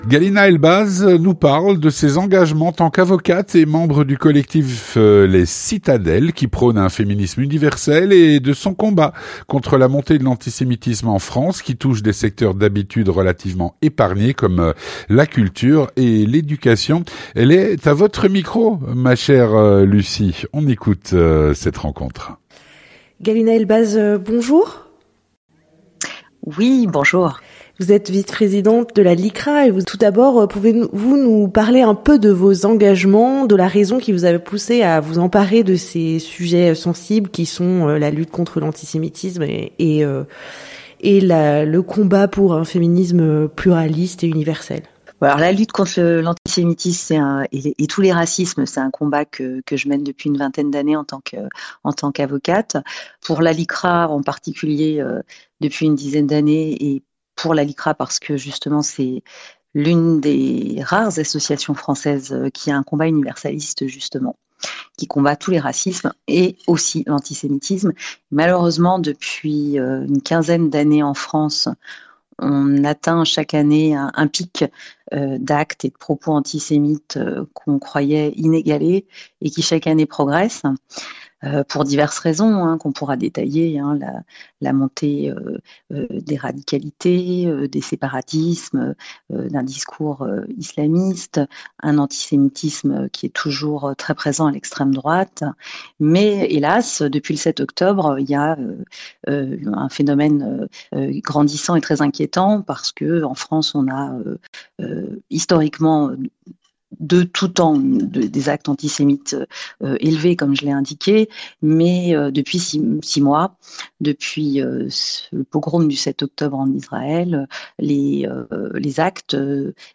Rencontre - La montée de l’antisémitisme en France touche des secteurs d’habitude relativement épargnés : la culture, l’éducation.